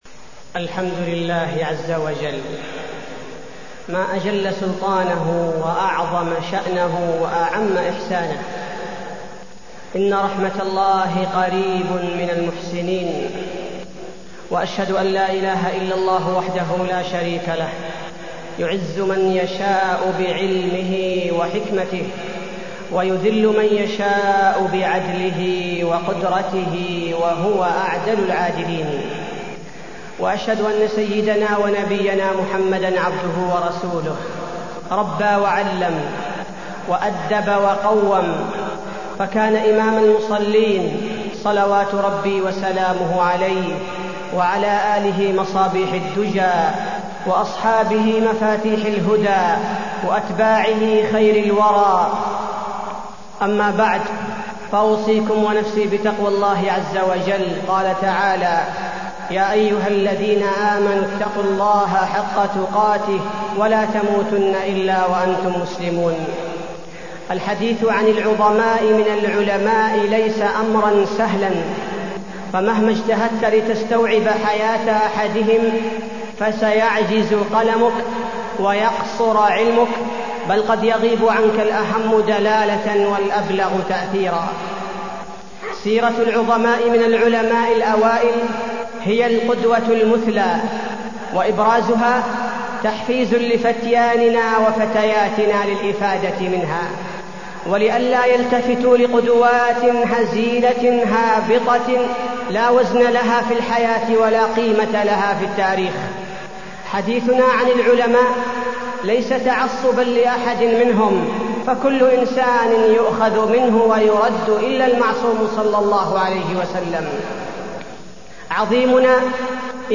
تاريخ النشر ٢٦ جمادى الآخرة ١٤٢٢ هـ المكان: المسجد النبوي الشيخ: فضيلة الشيخ عبدالباري الثبيتي فضيلة الشيخ عبدالباري الثبيتي إمام دار الهجرة